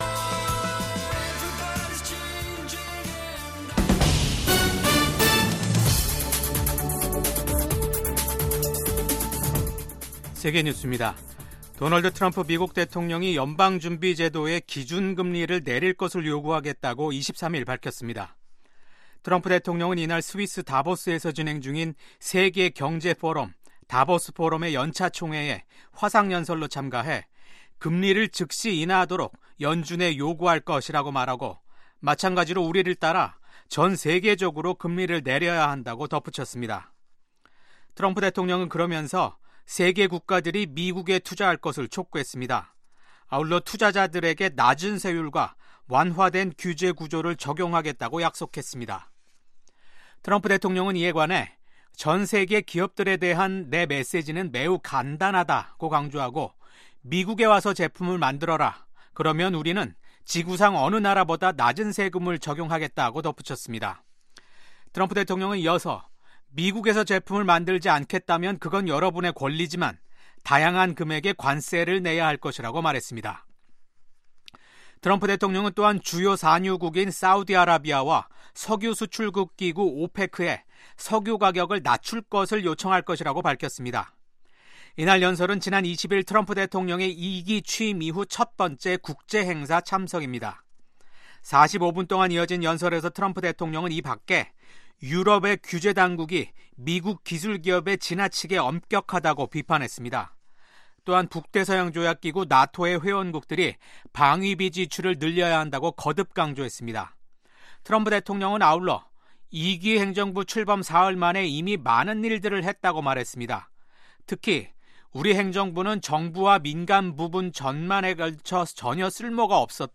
VOA 한국어 아침 뉴스 프로그램 '워싱턴 뉴스 광장'입니다. 미국 공화당 의원들은 도널드 트럼프 대통령의 두 번째 임기를 환영하며, 북한과 중국, 러시아, 이란 등 독재국가들에 대한 강경 대응을 예고했습니다.